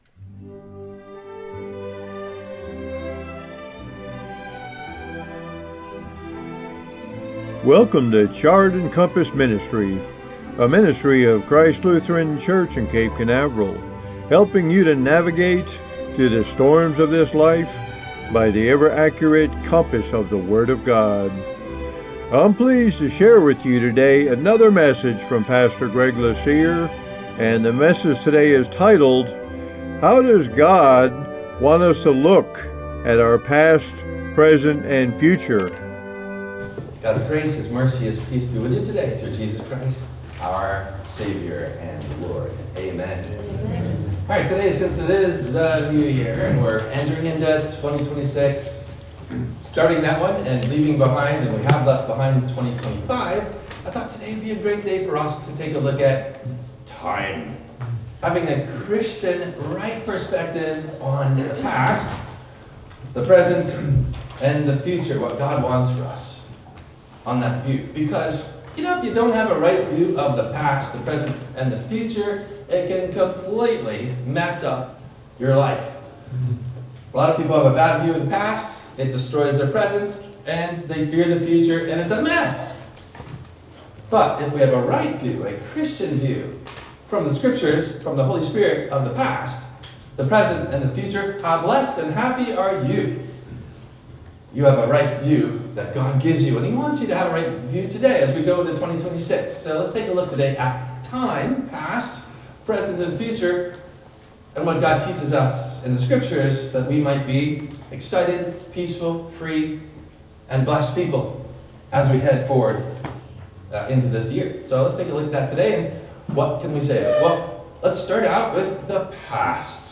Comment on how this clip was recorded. Length: 27:57 mins. Including Intro and closing Plug.